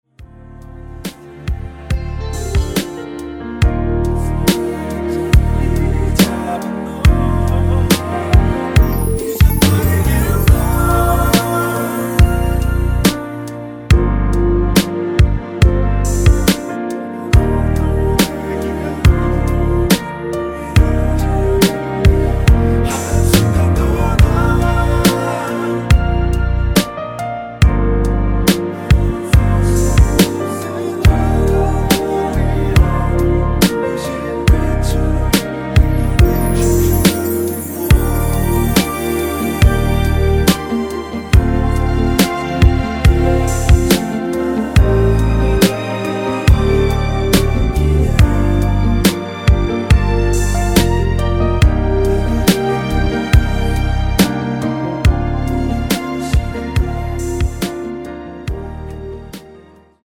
(-1)내린 코러스 포함된 MR 입니다.(미리듣기 참조)
Bb
앞부분30초, 뒷부분30초씩 편집해서 올려 드리고 있습니다.
곡명 옆 (-1)은 반음 내림, (+1)은 반음 올림 입니다.